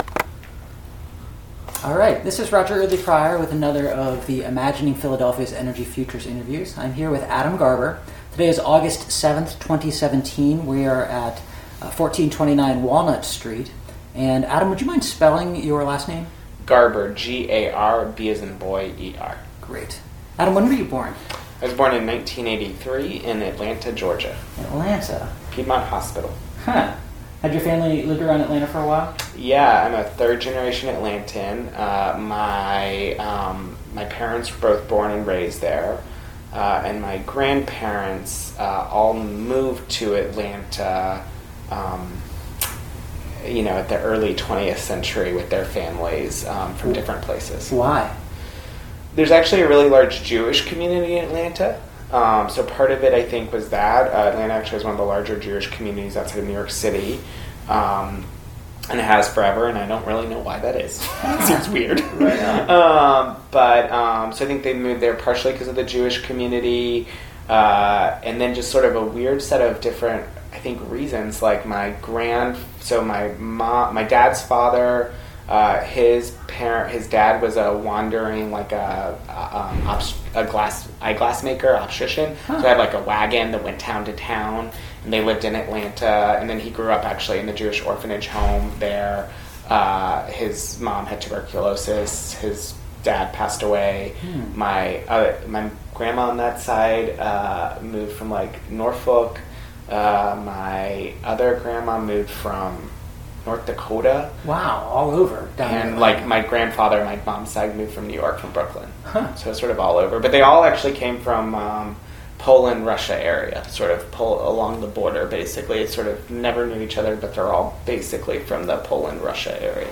Place of interview Pennsylvania--Philadelphia
Genre Oral histories